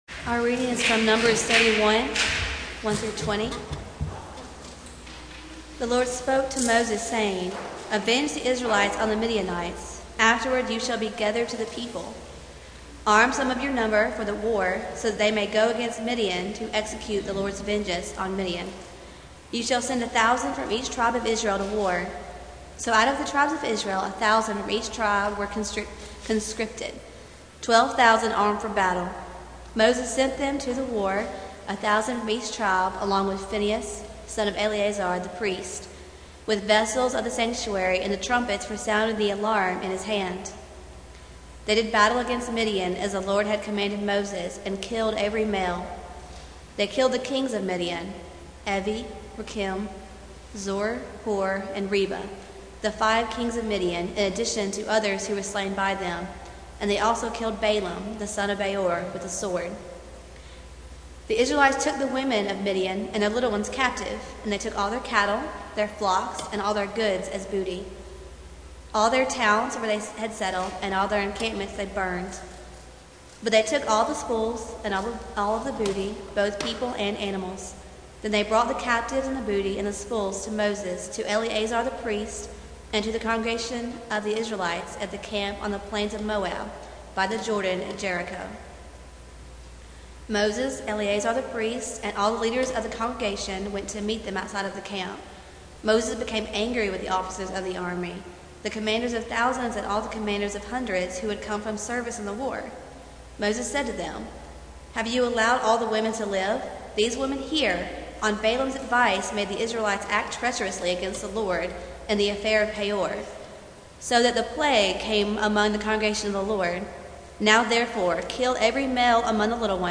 Passage: Numbers 31:1-20 Service Type: Sunday Morning